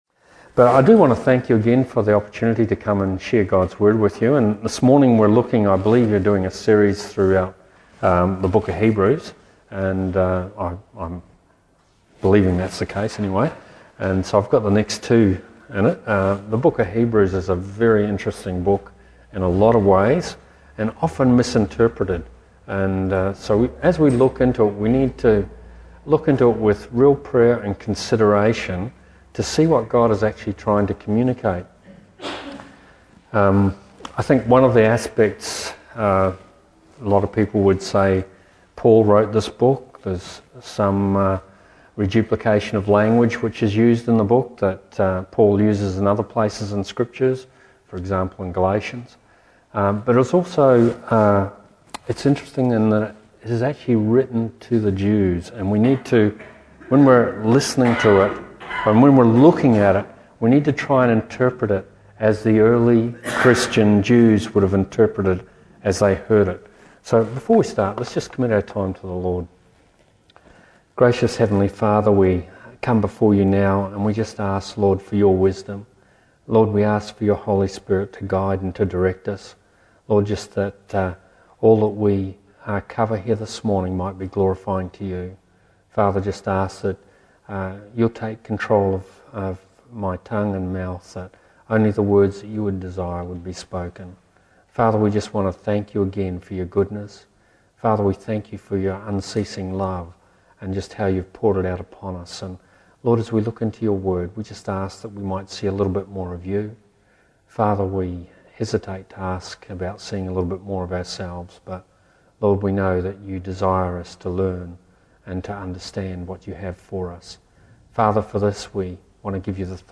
Service Type: Family Service